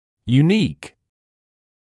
[juː’niːk][юː’ниːк]уникальный; единственный в своём роде; неповторимый